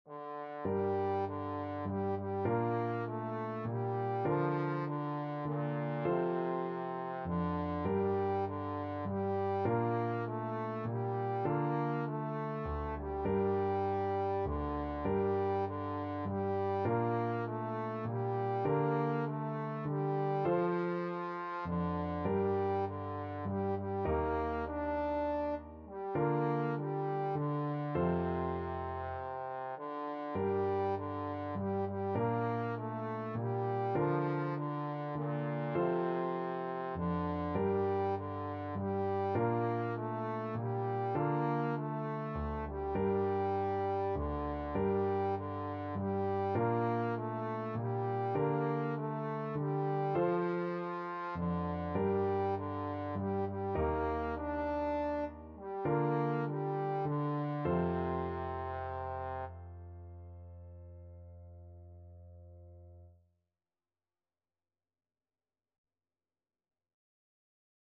Traditional Trad. I wonder as I wander (Appalacian carol) Trombone version
Trombone
6/8 (View more 6/8 Music)
G minor (Sounding Pitch) (View more G minor Music for Trombone )